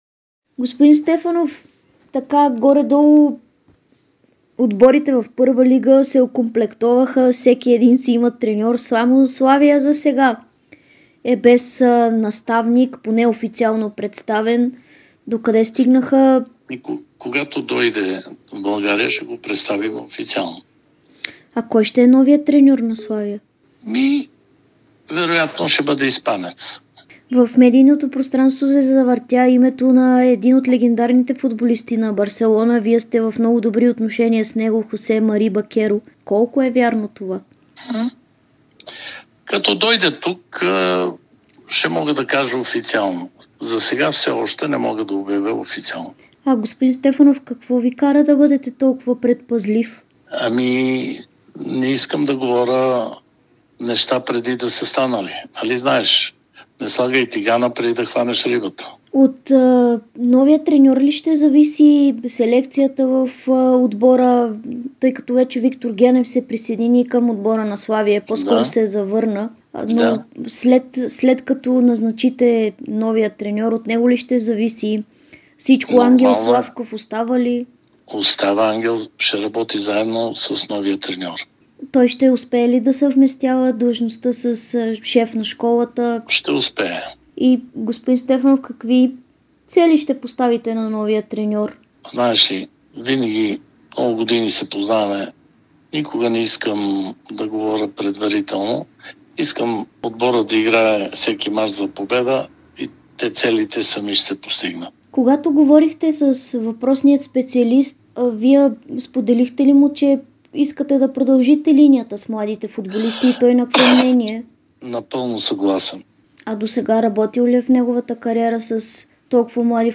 Президентът на Славия Венцеслав Стефанов говори пред Дарик радио и dsport преди началото на подготовката на "белите" относно новия треньор на столичани, интереса към неговите играчи и целите на клуба за новия шампионат.